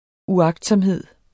Udtale [ uˈɑgdsʌmˌheðˀ ]